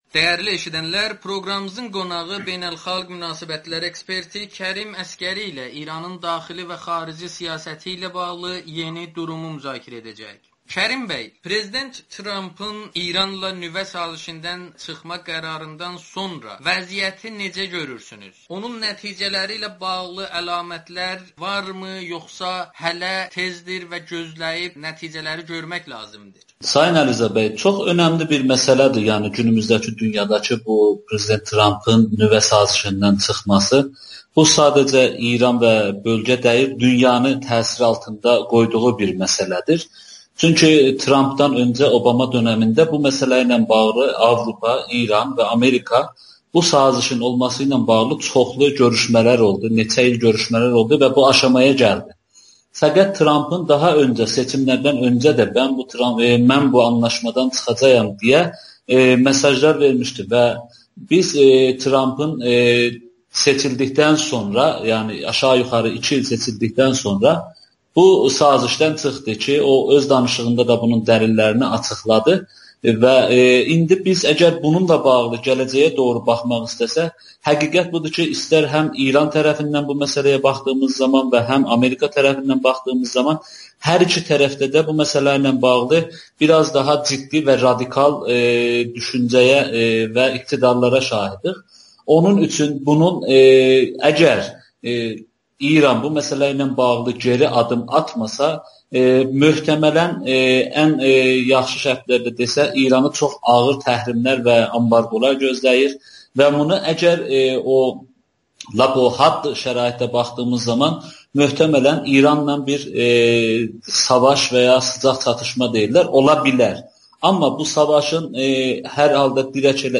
əhalinin süfrəsində görünmür [Audio-Müsahibə]